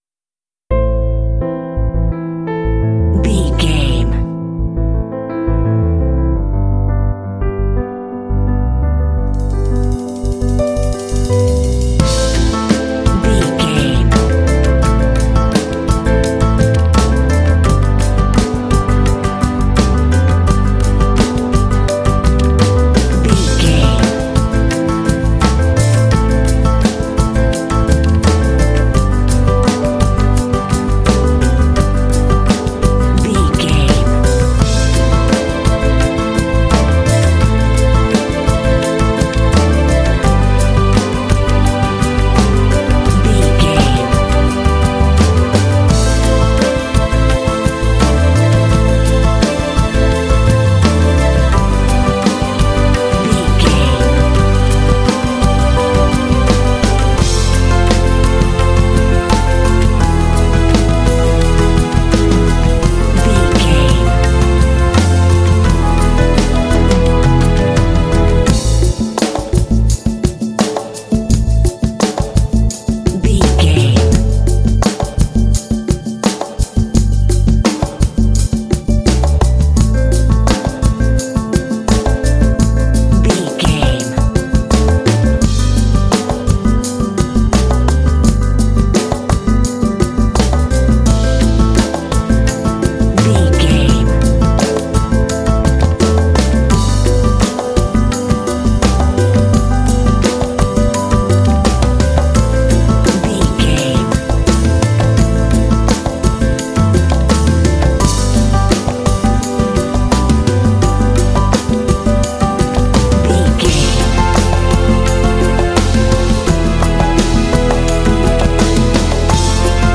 Aeolian/Minor
smooth
hopeful
dramatic
piano
strings
drums
symphonic rock
cinematic